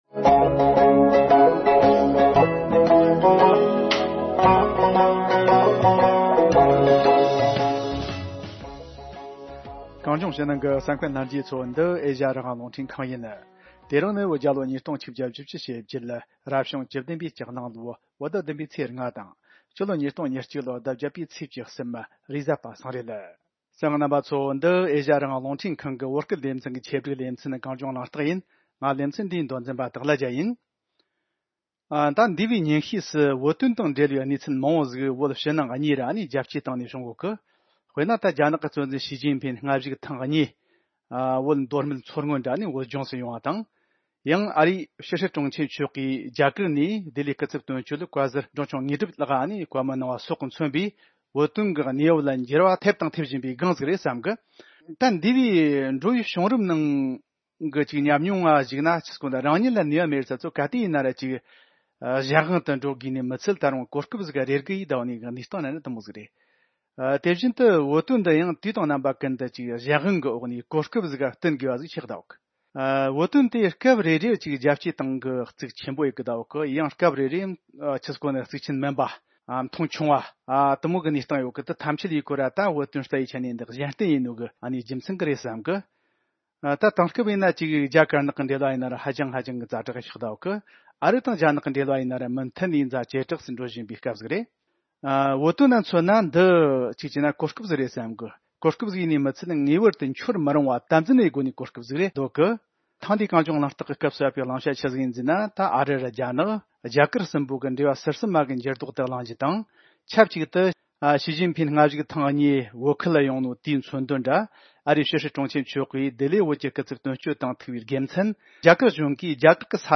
གླེང་མོལ་ཞུས་པའི་ལས་རིམ།